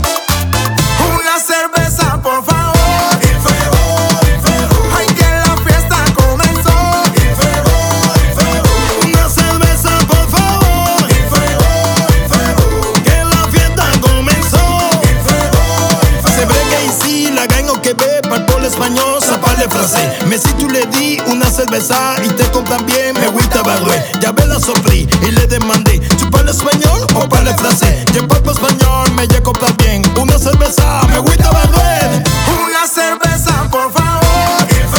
Жанр: Реггетон